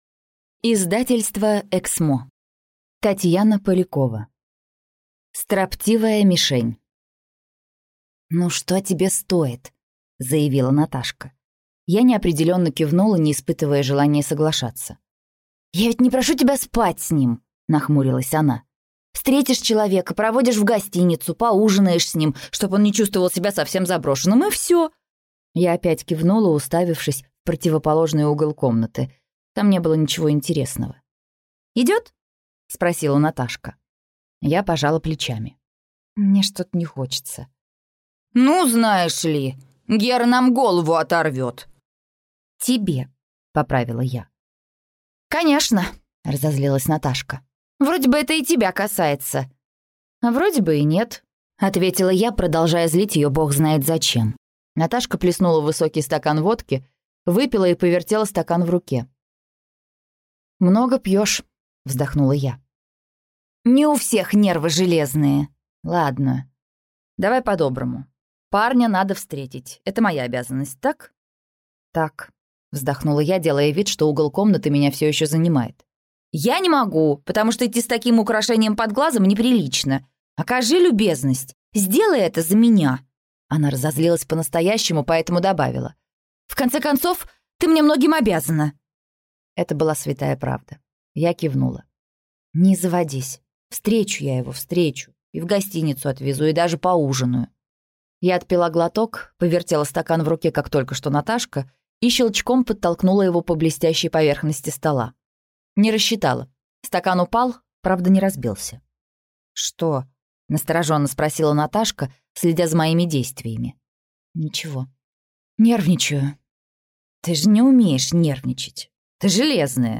Аудиокнига Строптивая мишень | Библиотека аудиокниг